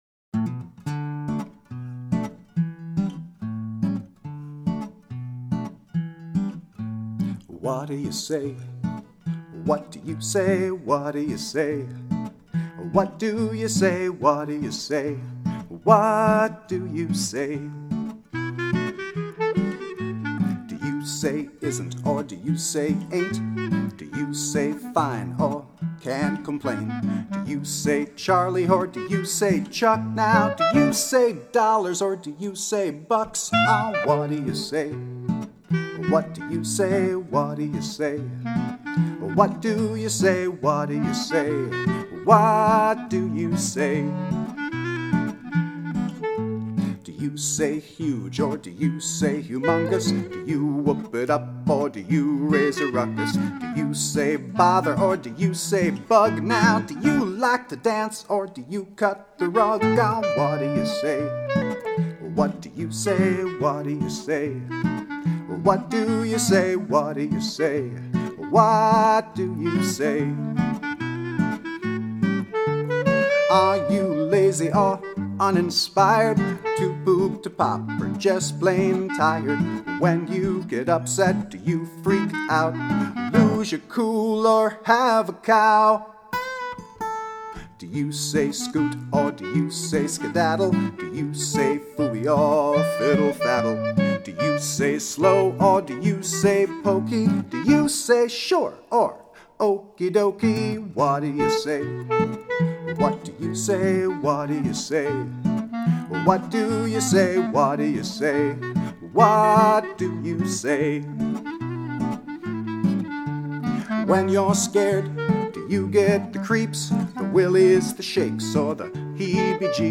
(Click on the link below to give a listen and, when you catch on to the chorus, sing along!)
guitar & vocals by
clarinet